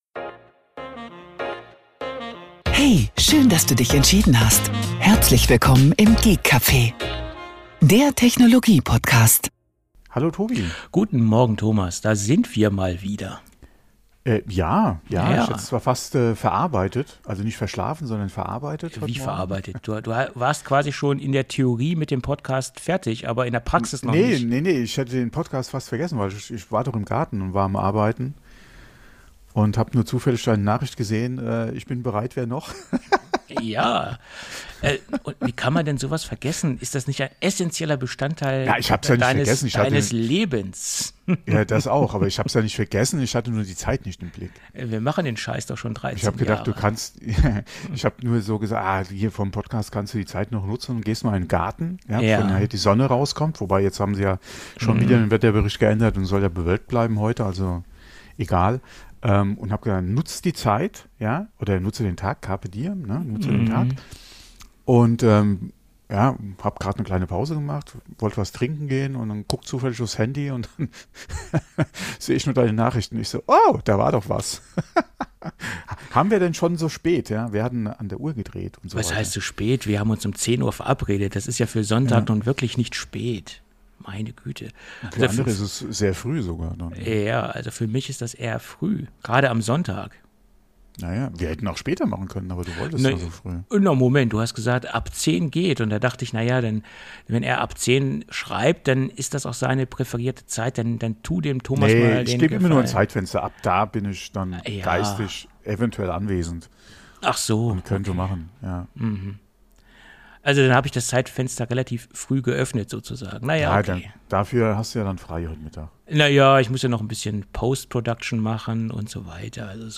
Die lockere Atmosphäre während der Aufnahme ist dem Konzept – oder eben dem Fehlen desselbigen zu verdanken.